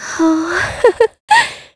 Ripine-Vox_Happy5_kr.wav